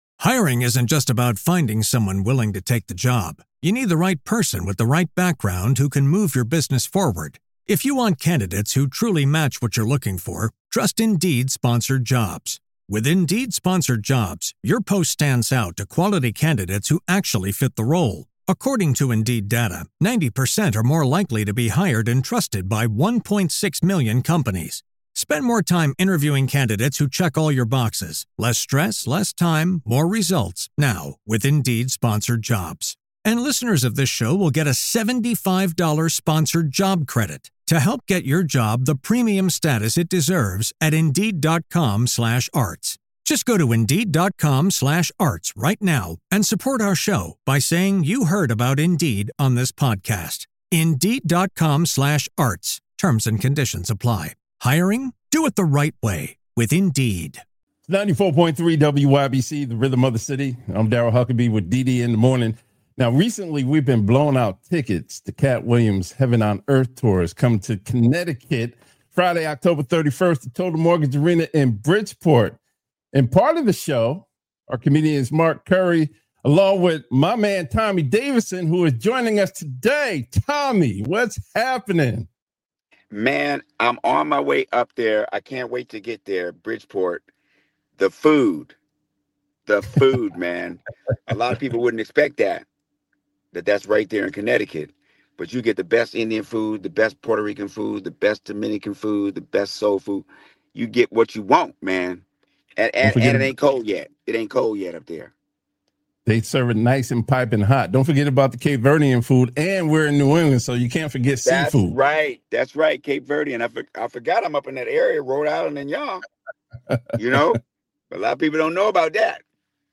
talks with comedian Tommy Davidson, who will open for Katt Williams' "Heaven on Earth" Tour at Total Mortgage Arena on October 31, 2025!